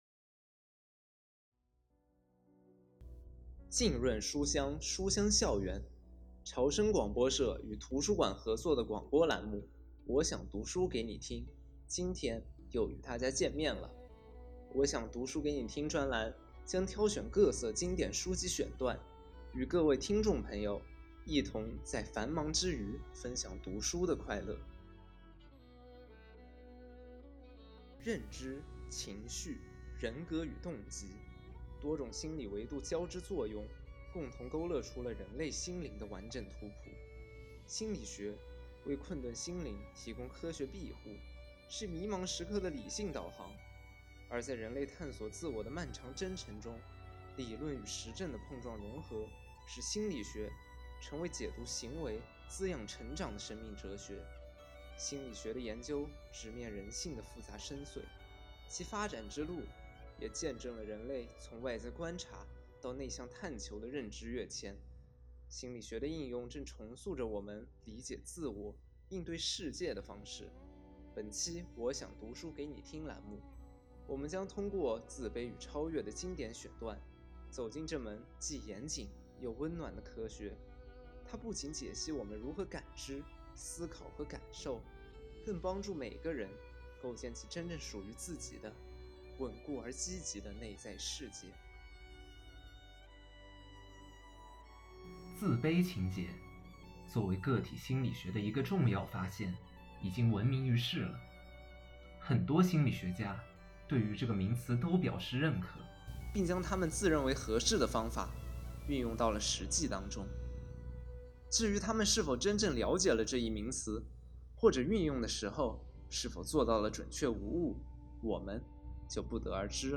第三十二期自卑与超越广播音频.mp3